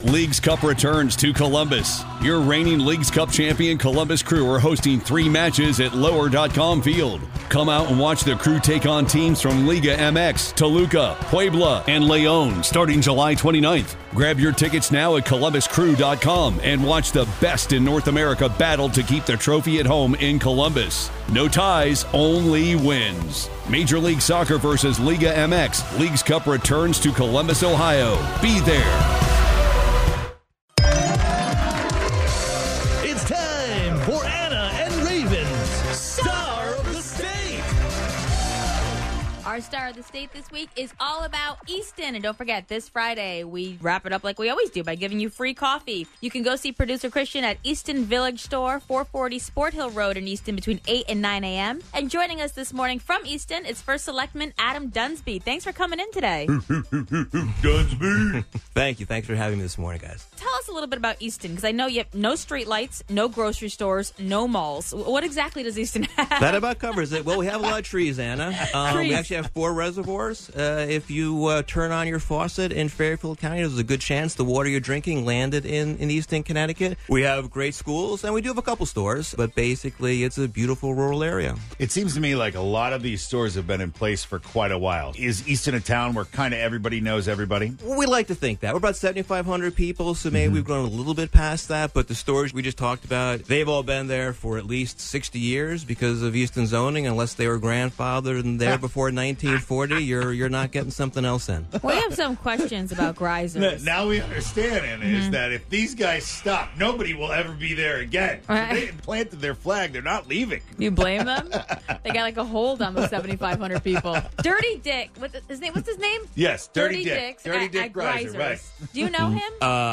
Our STAR of the state this week is all about Easton! First Selectman and Easton resident Adam Dunsby stopped by to talk about all the great things in Easton!